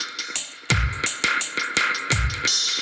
Index of /musicradar/dub-designer-samples/85bpm/Beats
DD_BeatC_85-01.wav